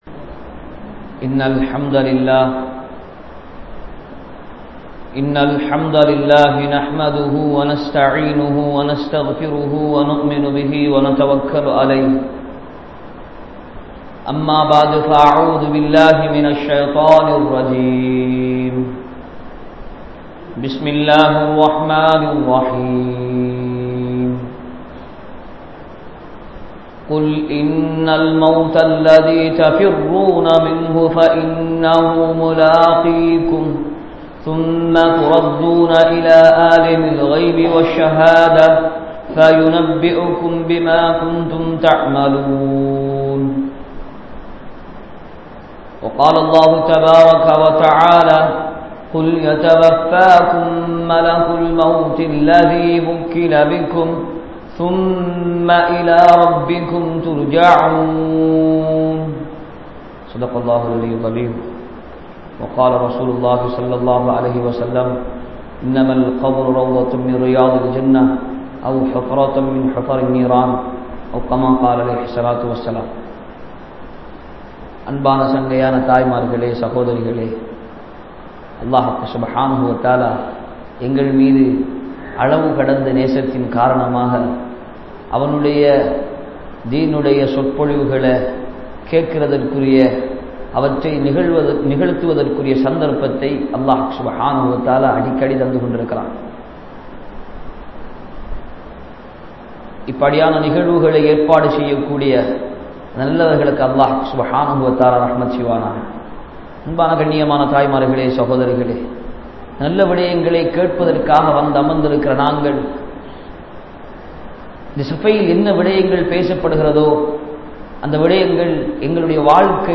Kaburudaiya Vaalkai (கப்ருடைய வாழ்க்கை) | Audio Bayans | All Ceylon Muslim Youth Community | Addalaichenai